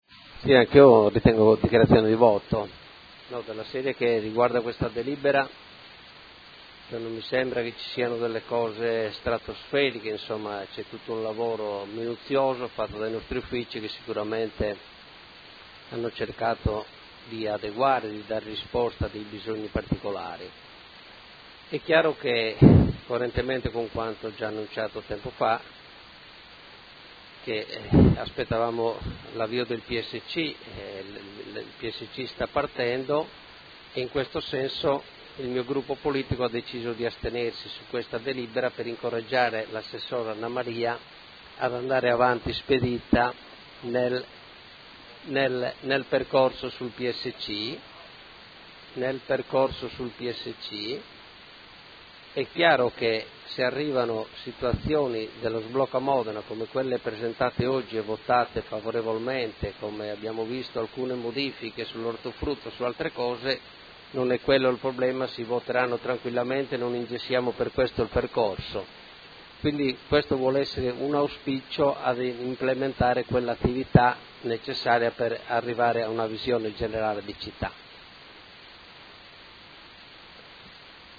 Seduta del 22 ottobre.
Dichiarazione di voto